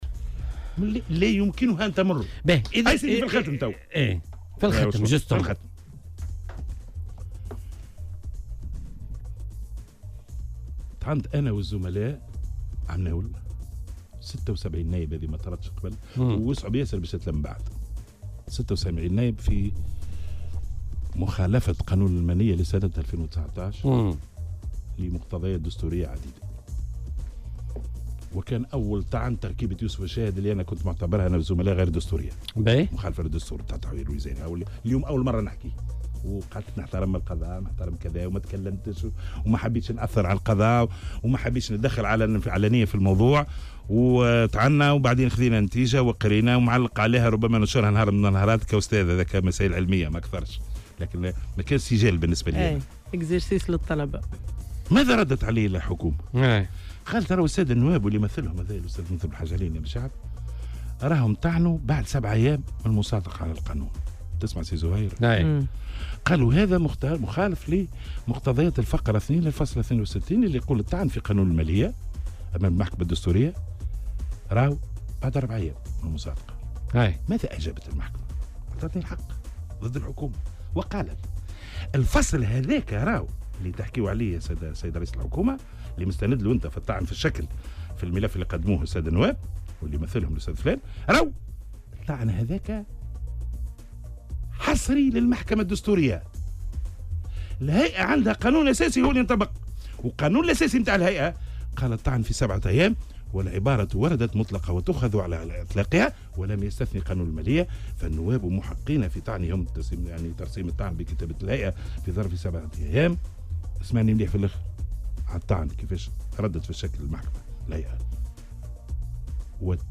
أكد النائب منذر بلحاج علي، أنّ التدابير ليست كلها مطلقة بخصوص ختم القوانين في علاقة برئيس الجمهورية والمحكمة الدستورية. وأوضح ضيف "بوليتيكا" على "الجوهرة أف أم" أن القضاء الدستوري منح حق الفيتو لرئيس الجمهورية لاتخاذ القرار المناسب في حالات معقّدة بمقتضى الفصل 72 من الدستور، معتبرا عدم ختم القانون الانتخابي قرار دستوريا.